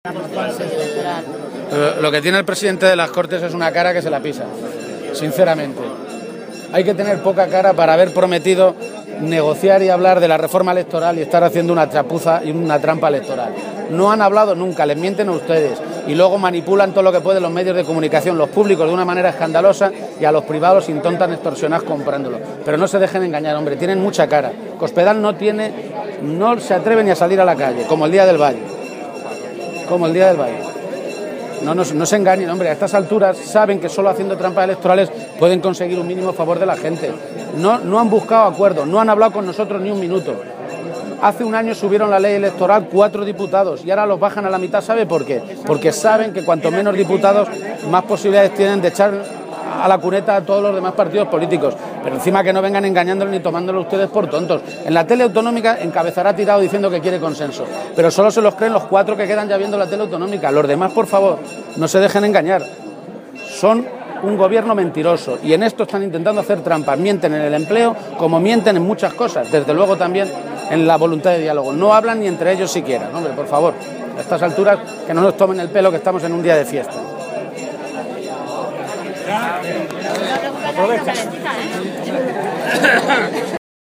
García-Page se pronunciaba de esta manera en declaraciones a los medios de comunicación durante la tradicional y toledana Romería del Valle.
Cortes de audio de la rueda de prensa